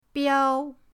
biao1.mp3